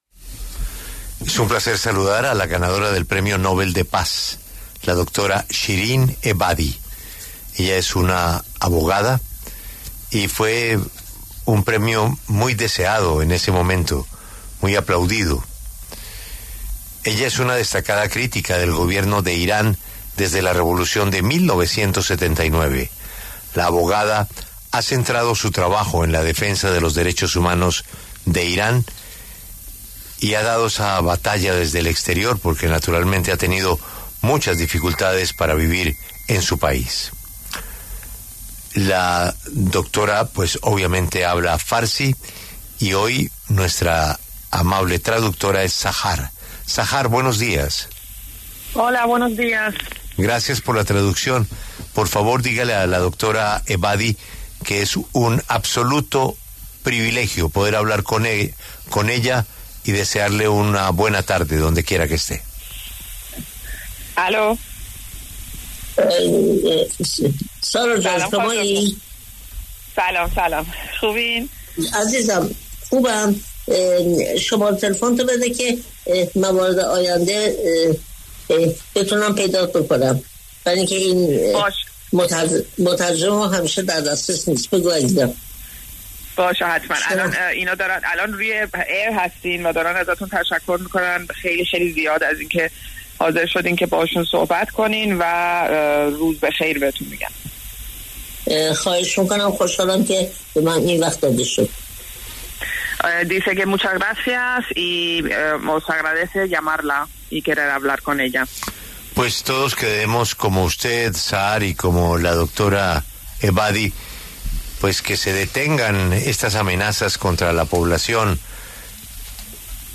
La doctora Shirin Ebadi, abogada iraní y premio Nobel de la Paz en 2003, conversó en La W sobre la situación actual en el conflicto entre Israel e Irán.